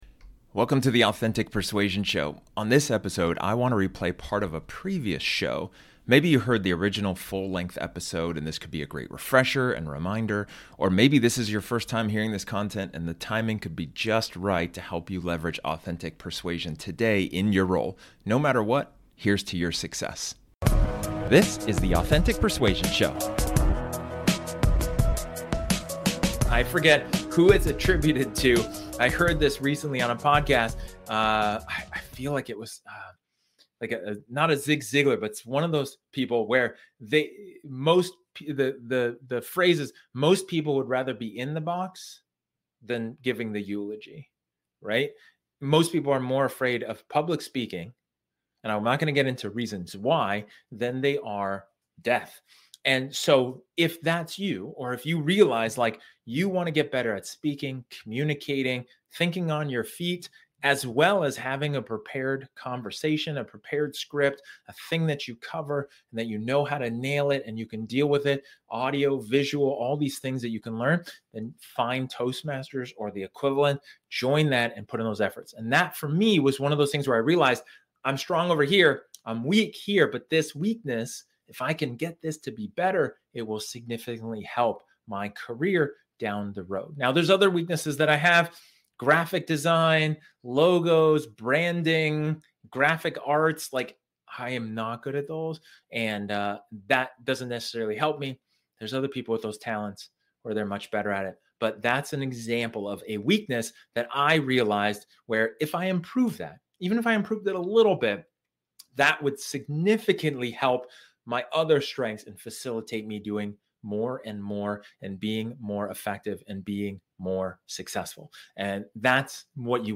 In this episode, This episode is an excerpt from one of my training sessions where I talk about the importance of acknowledging your strengths.